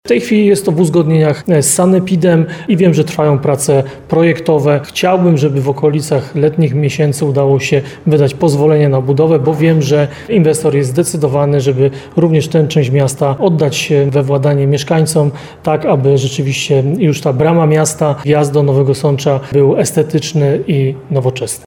Już w zeszłym roku prywatny inwestor uzyskał zgodę Małopolskiego Konserwatora Zabytków na remont i modernizację. Jak informuje prezydent Nowego Sącza, Ludomir Handzel, inwestycja jest na dobrej drodze.